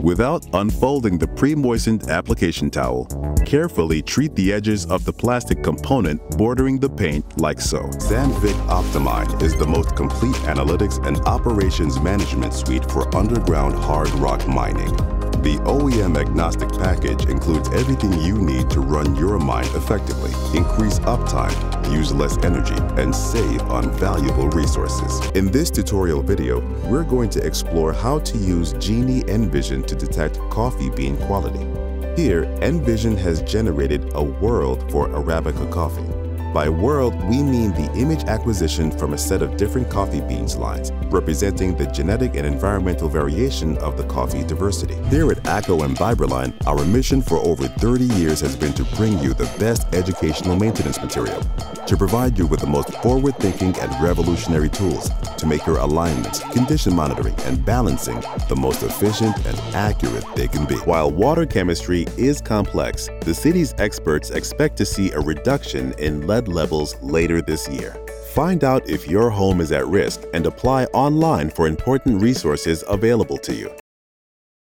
Male
Approachable, Assured, Authoritative, Confident, Conversational, Cool, Corporate, Deep, Engaging, Friendly, Gravitas, Natural, Reassuring, Smooth, Soft, Streetwise, Upbeat, Warm
American Southern, African American Vernacular English, Jamaican patois, UK (South London), New Yorker
Microphone: TLM103/MKH416